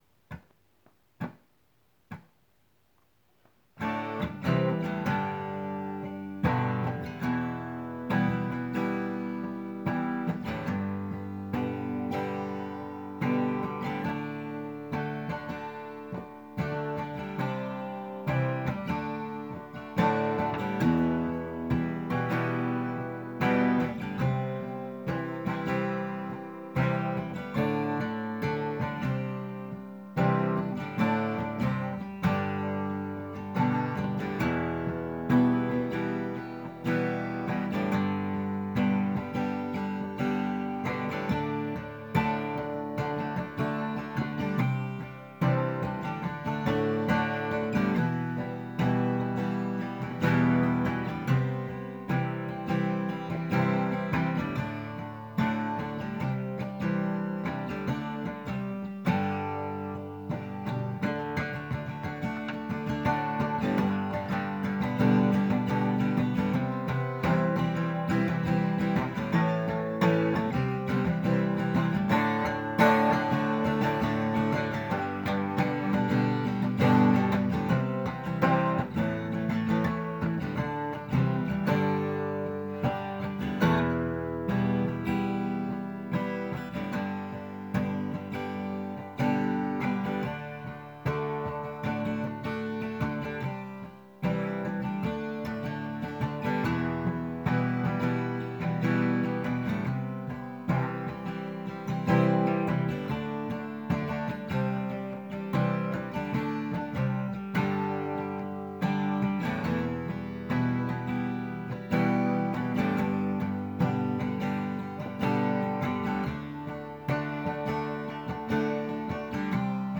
music_smorgasbord_littleleague_acoustic.m4a